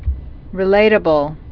(rĭ-lātə-bəl)